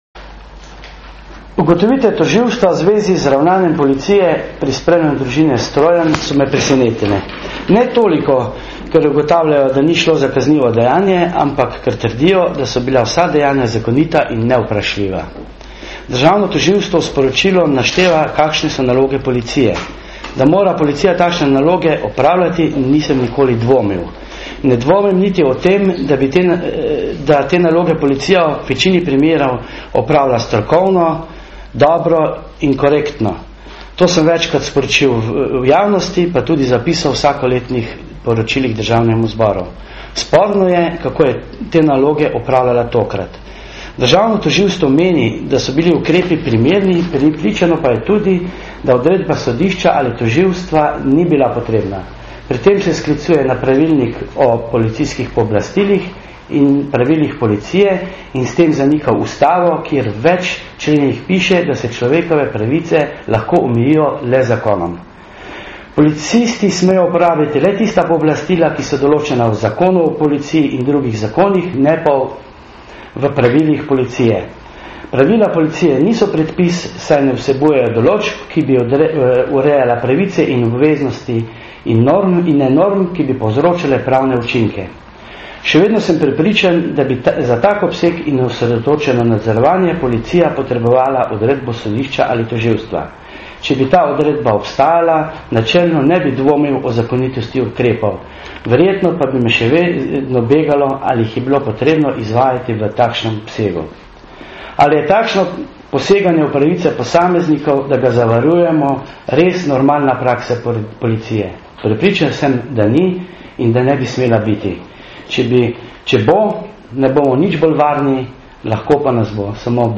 Matjaž Hanžek
Zvočni posnetek izjave, ki se od tiskanega razlikuje v drugem odstavku najdete